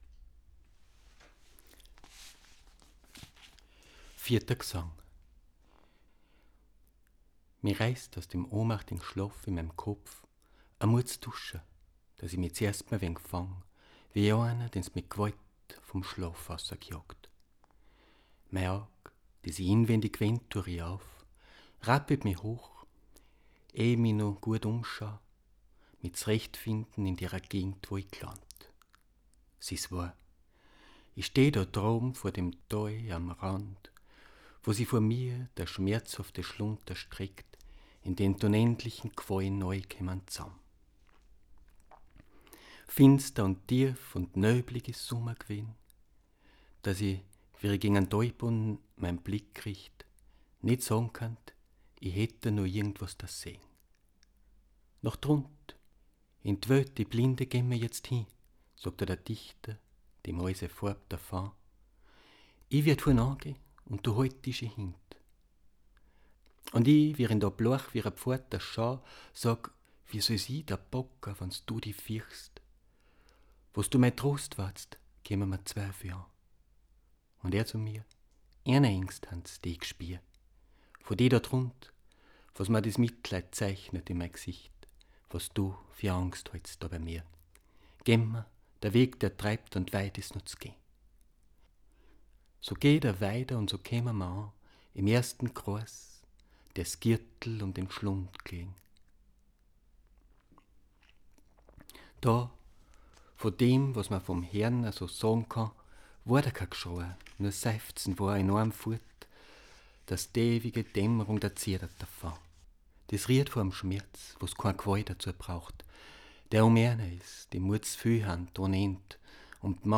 - ♫ Erste Audio-Skizze - (one-take recording - verleser ausgschnittn) ♫